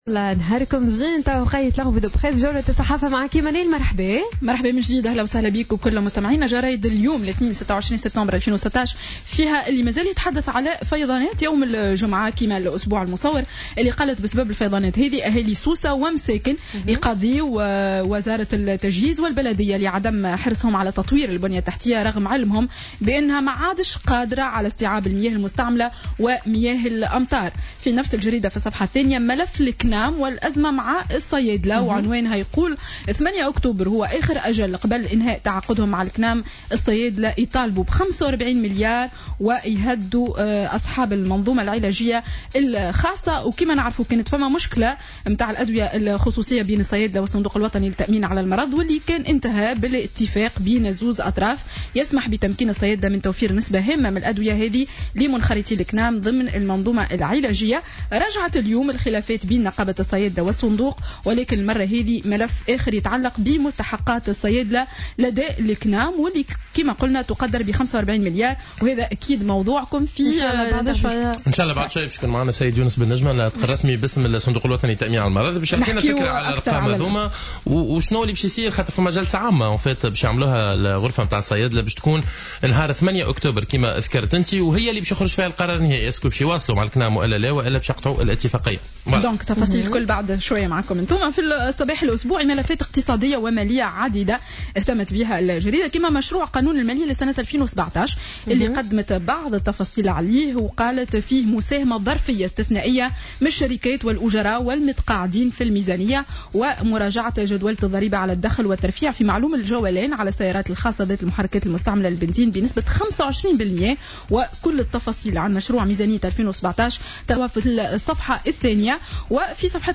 معرض الصحافة ليوم الاثنين 26 سبتمبر 2016